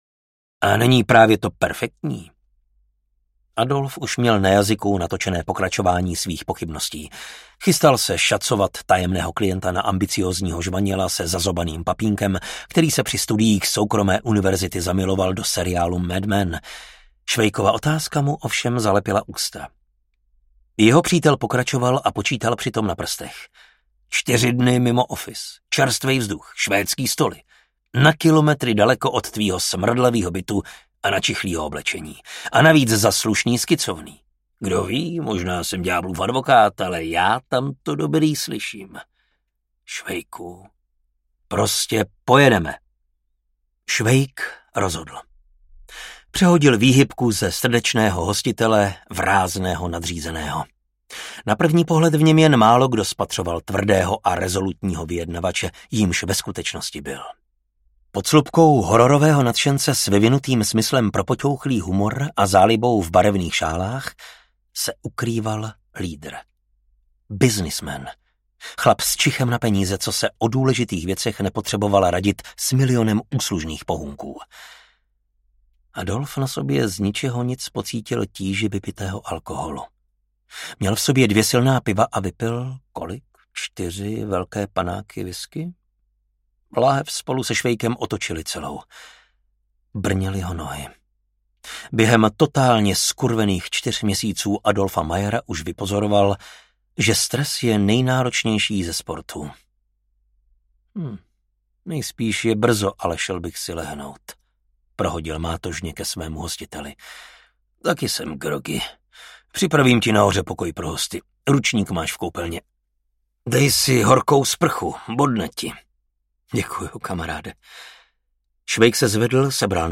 Druhý dech audiokniha
Ukázka z knihy
Vyrobilo studio Soundguru.